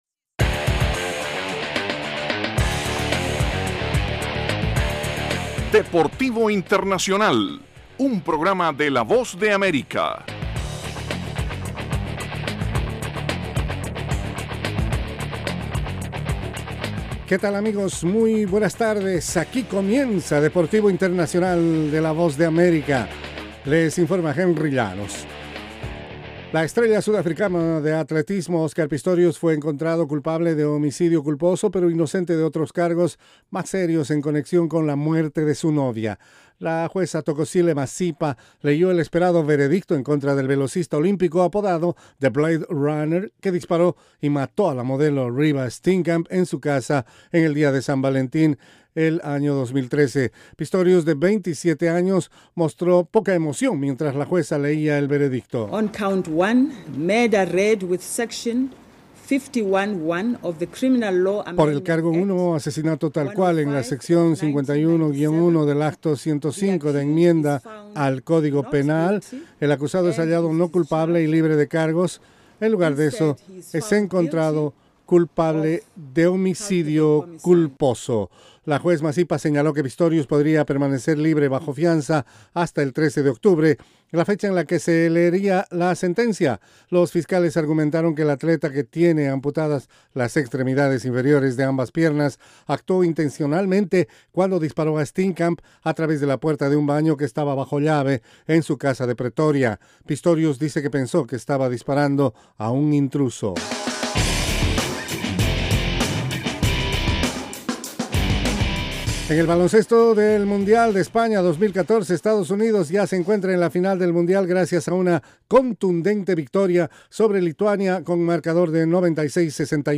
presenta las noticias más relevantes del mundo deportivo desde los estudios de la Voz de América. Deportivo Internacional se emite de lunes a viernes, de 12:05 a 12:10 de la tarde (hora de Washington).